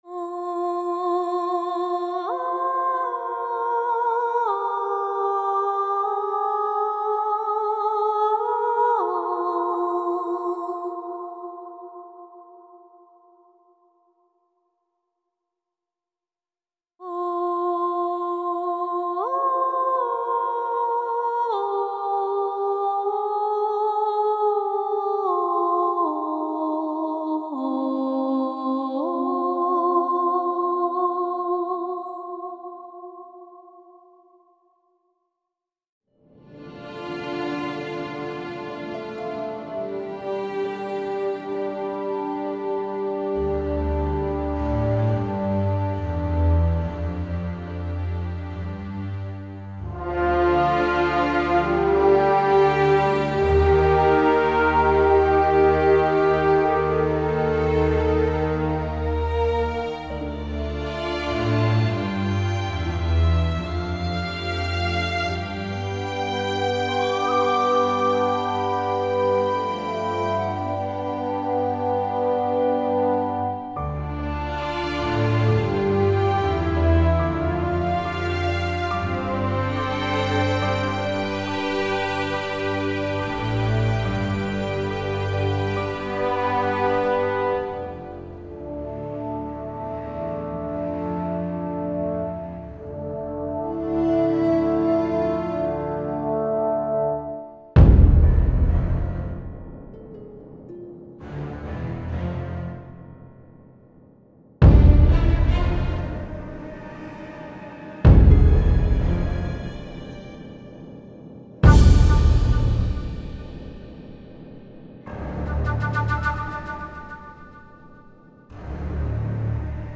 Elle semble en accords avec l'atmosphère de ce paysage.